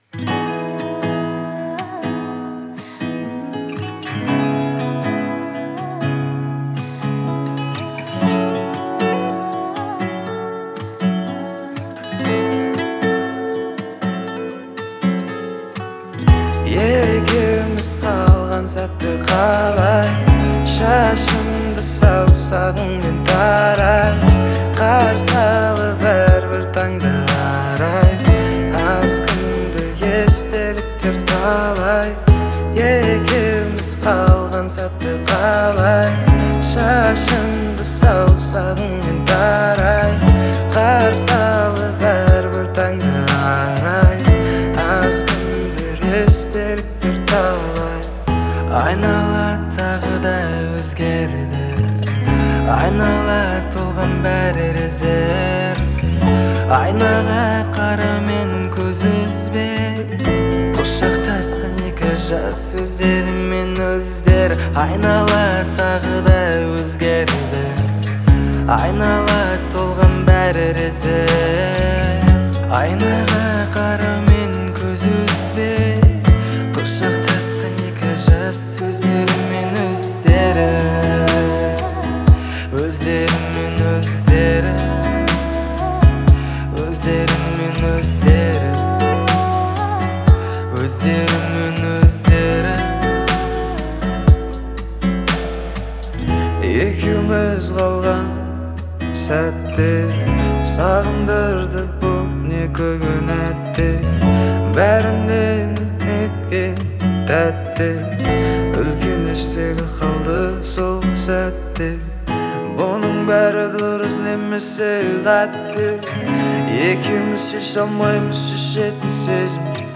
это яркая композиция в жанре поп с элементами R&B